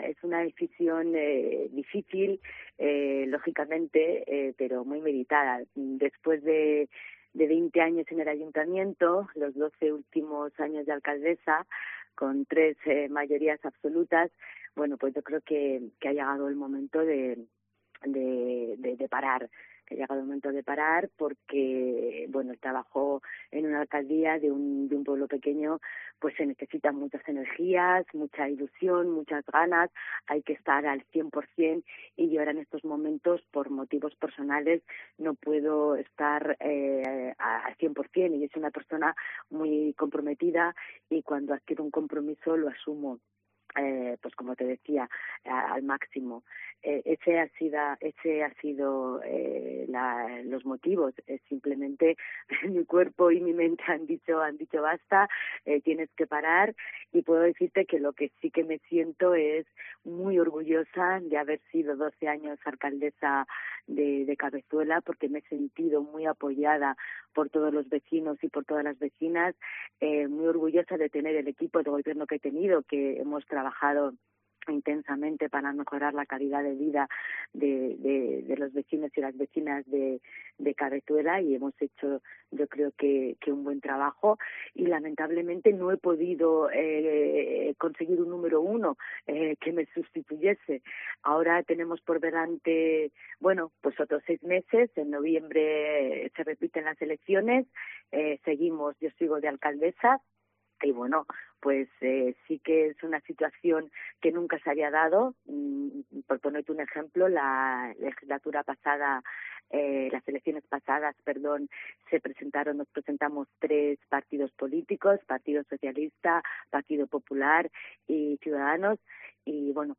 AUDIO: Son sus primeras declaraciones desde que se confirmase que no concurrirá a la reelección el 28-M y que no se han presentado candidaturas en...